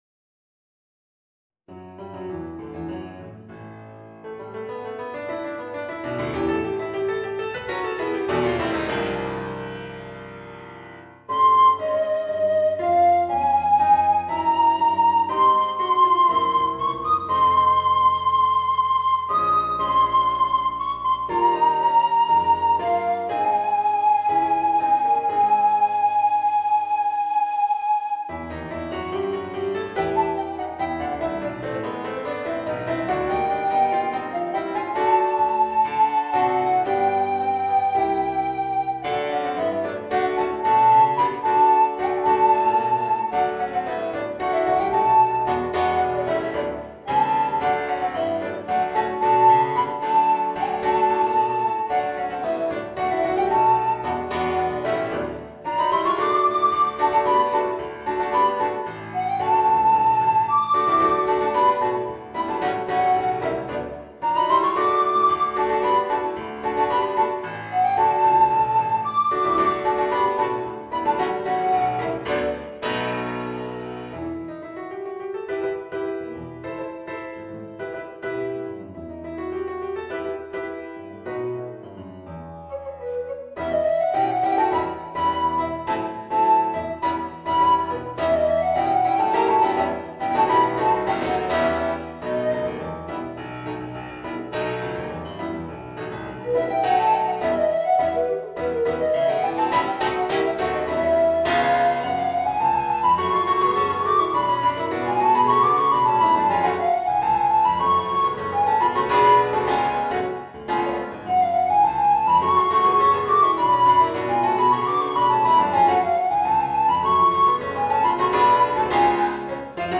Voicing: Alto Recorder and Piano